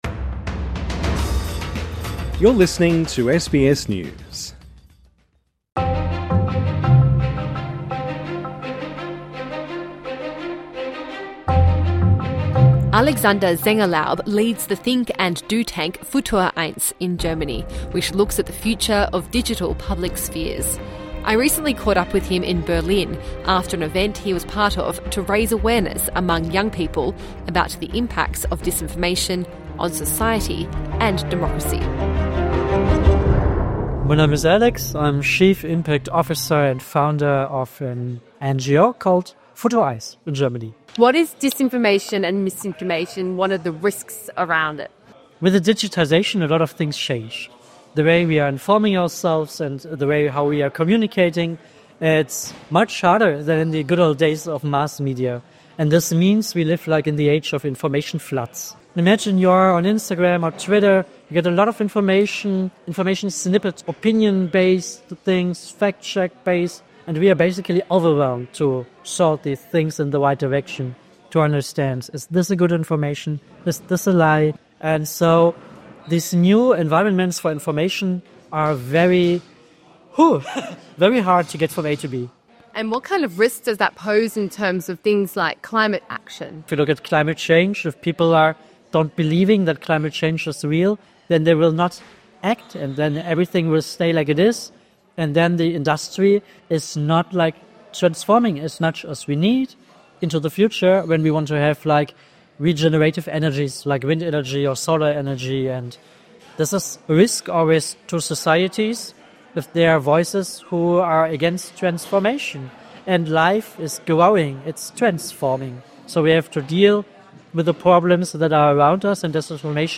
INTERVIEW: The planet at risk - from disinformation